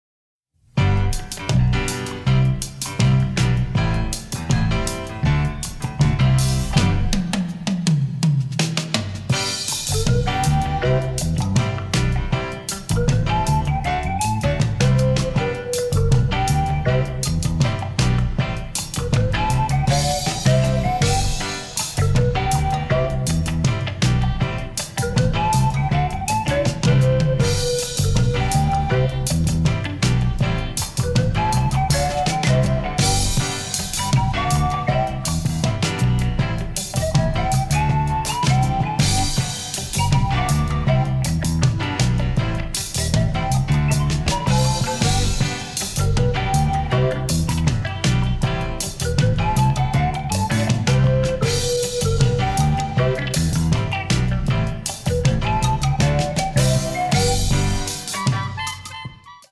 The score was created with a big band